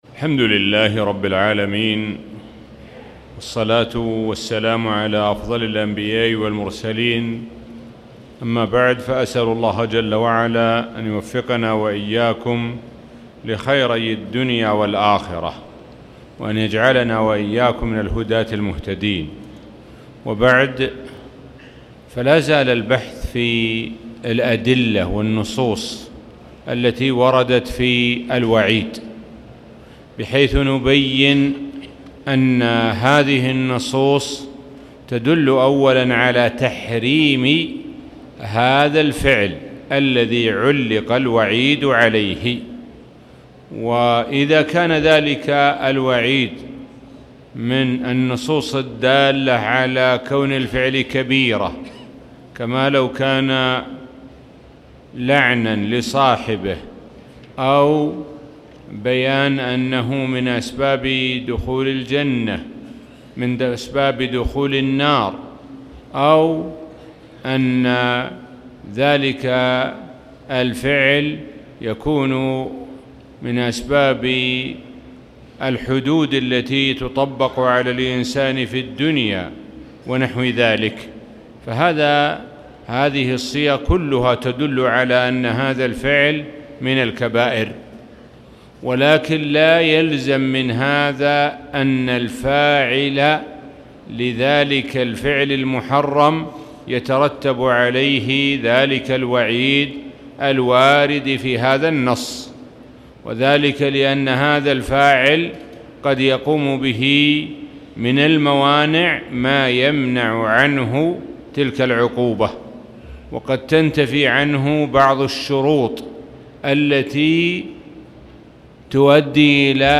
الموقع الرسمي لفضيلة الشيخ الدكتور سعد بن ناصر الشثرى | الدرس--6 رفع الملام عن الأئمة الأعلام